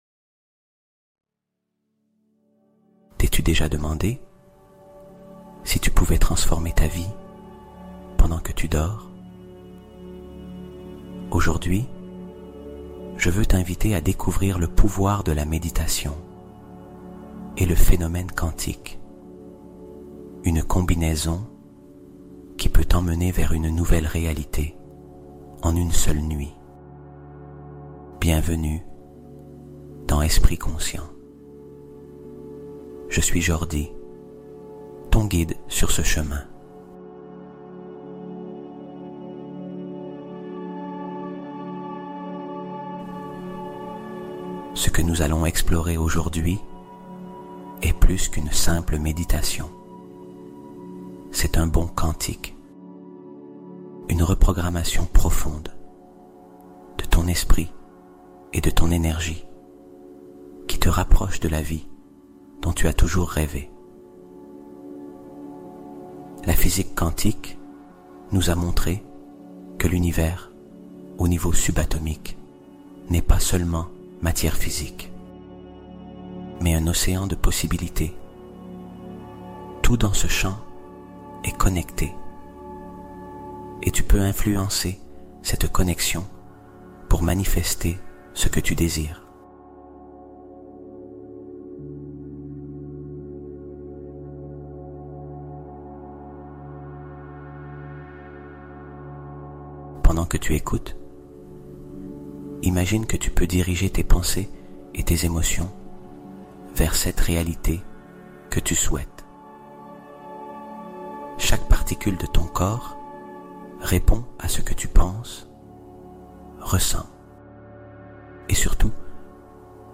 Pendant Que Tu Dors, CECI Répare Ton Âme : Méditation de Guérison Automatique (0 Effort Requis)